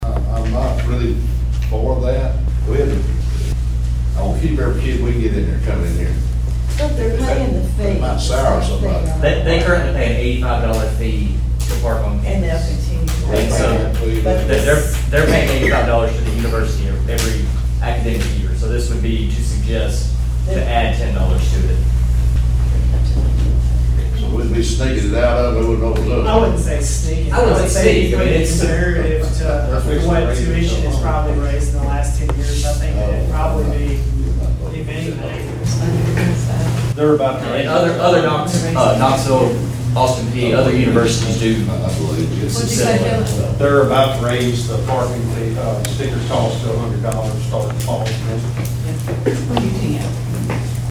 At Martin’s Finance Committee meeting last week, the committee discussed a plan to help lower the City’s debt by charging UTM students….
The other committee members gave their input on whether or not it would be a good decision for the City.